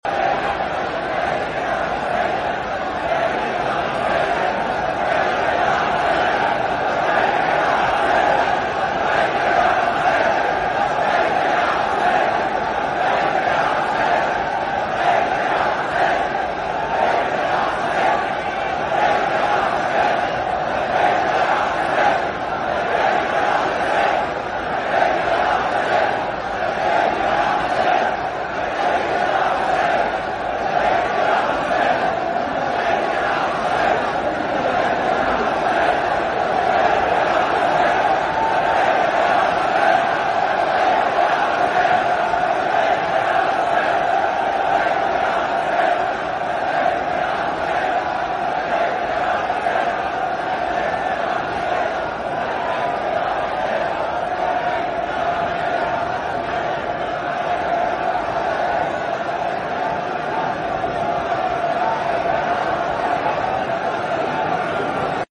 Light switch green to red sound effects free download